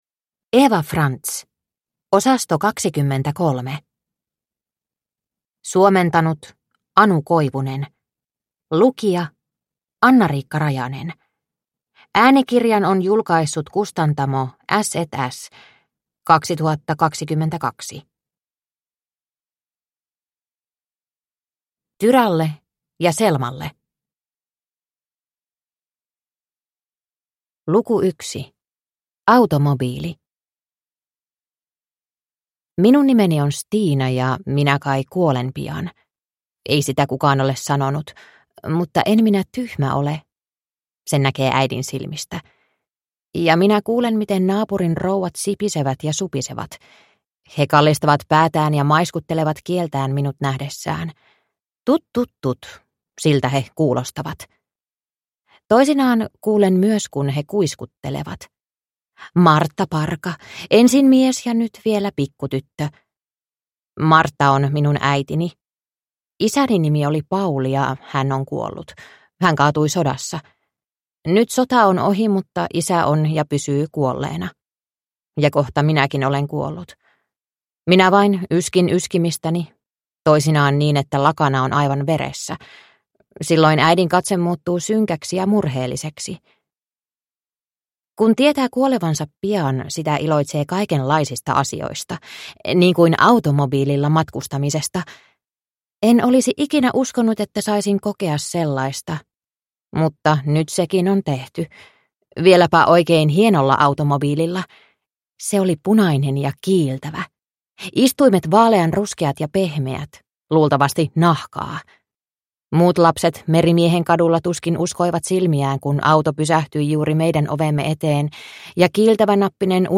Osasto 23 – Ljudbok – Laddas ner